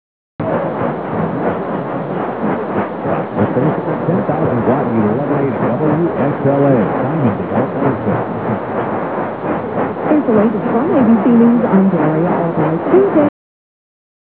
This page contains DX Clips from the 2008 DX season!